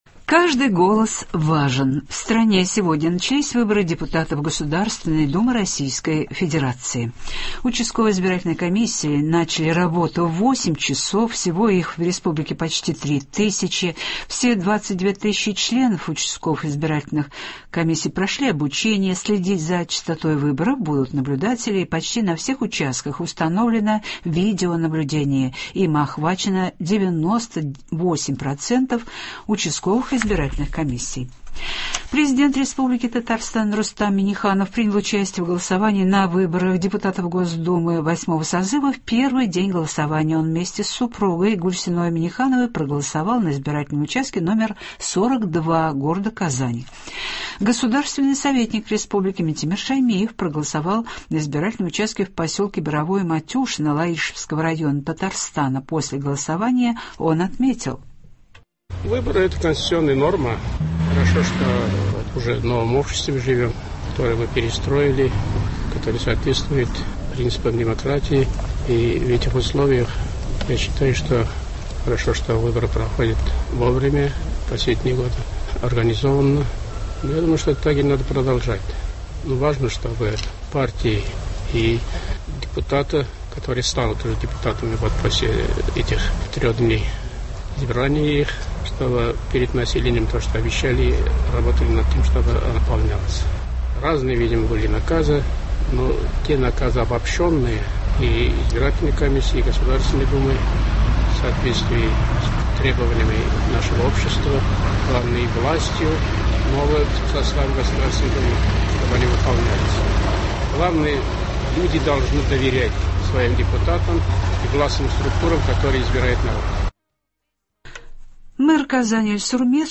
Утренний выпуск. Будущее избирательной системы за дистанционным электронным голосованием, однако традиционное голосование тоже никуда не денется.